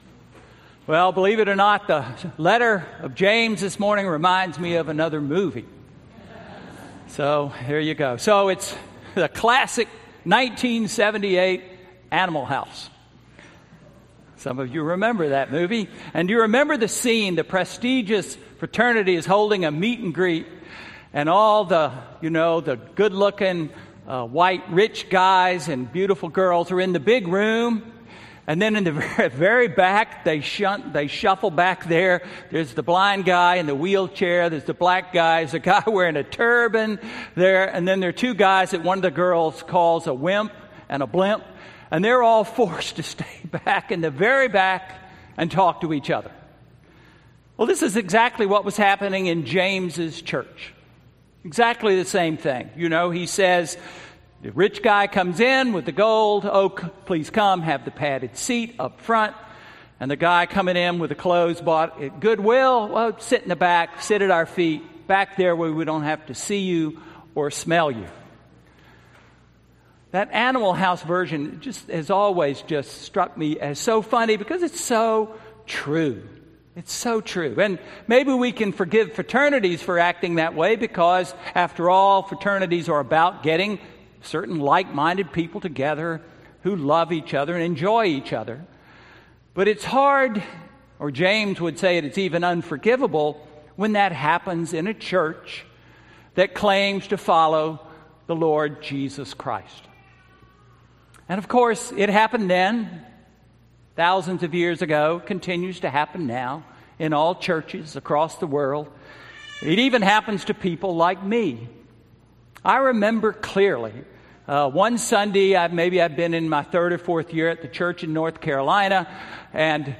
Sermon–September 6, 2015